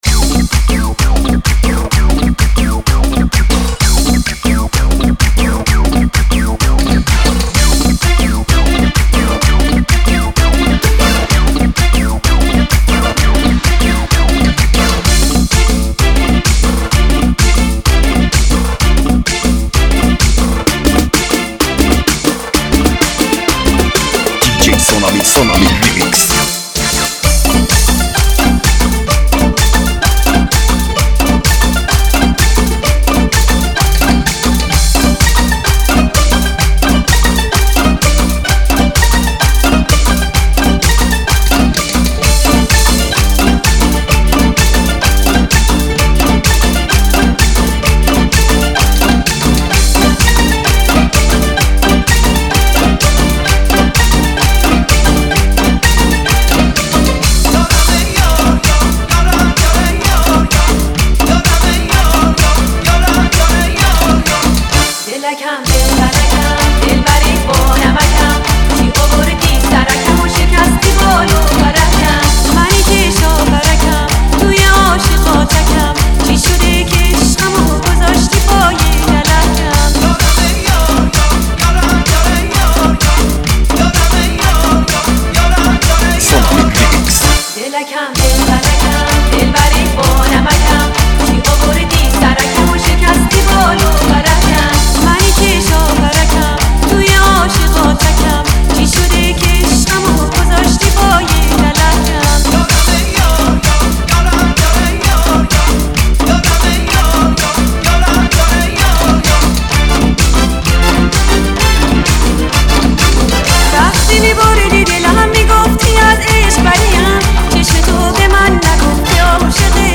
ریمیکس دوم